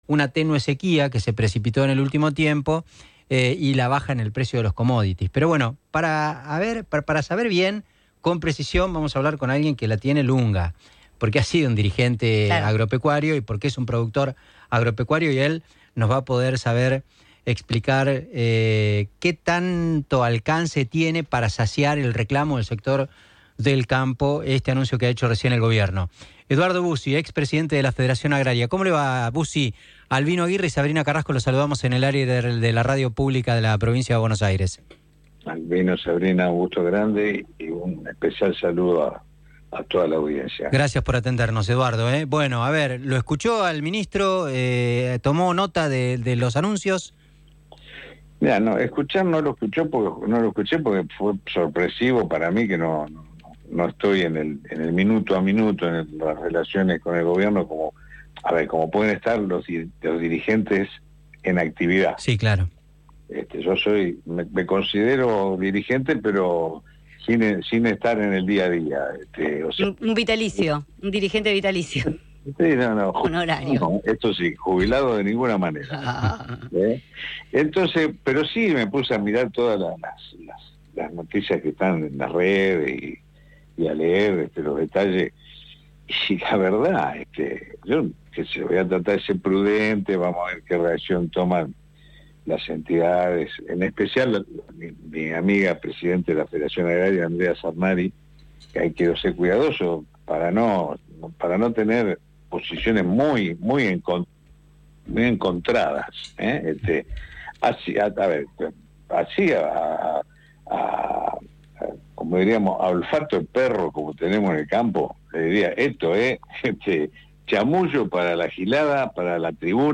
Entrevista al Dirigente y productor agropecuario. Ex titular de la Federación Agraria en «Siempre es Hoy»